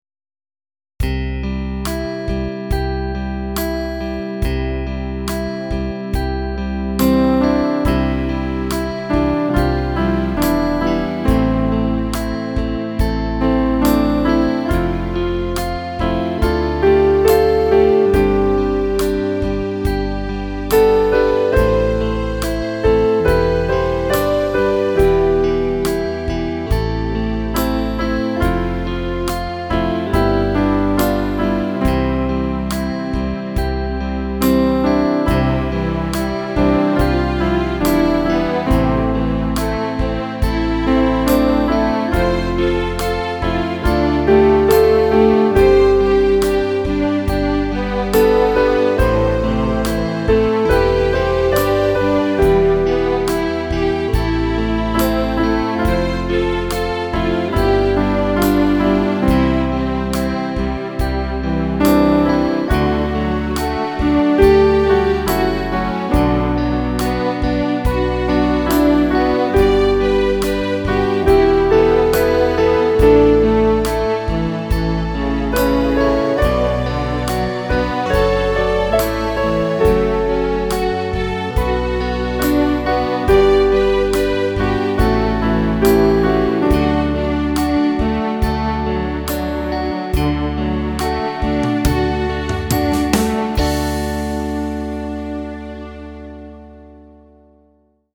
Instrumentalaufnahme